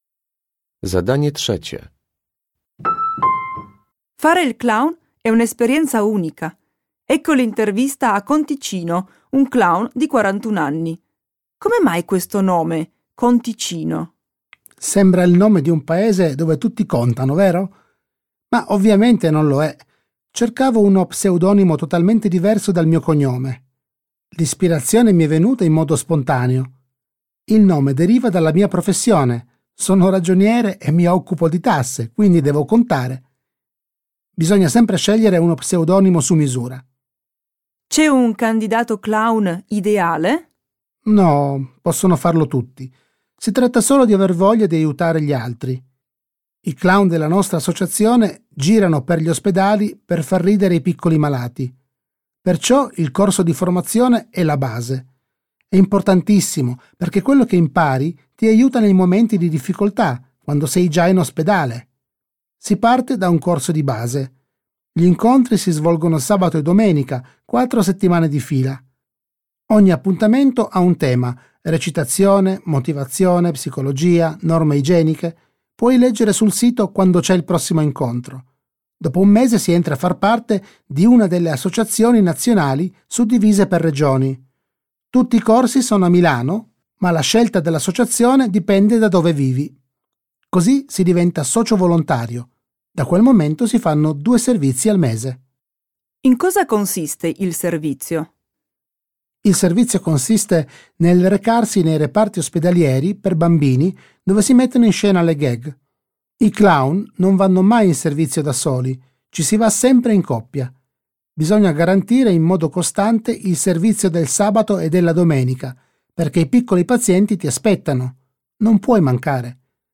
Uruchamiając odtwarzacz z oryginalnym nagraniem CKE usłyszysz dwukrotnie wywiad z klaunem amatorem.